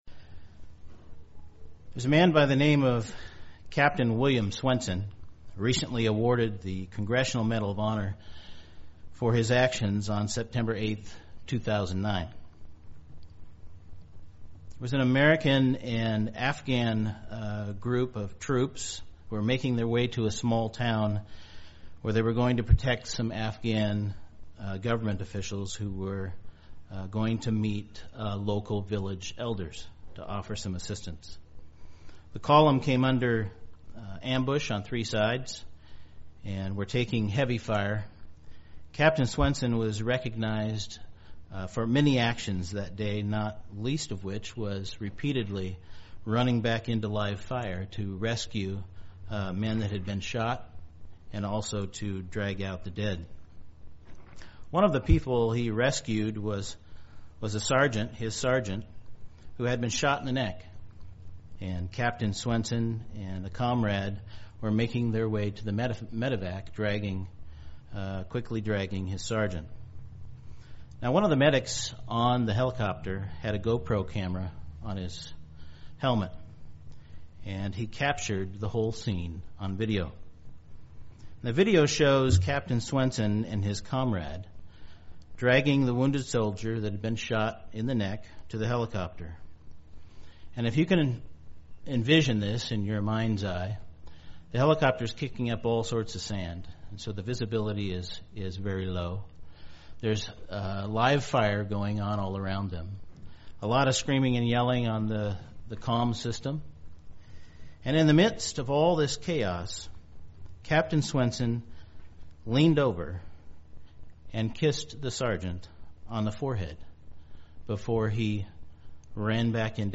Given in Medford, OR Central Oregon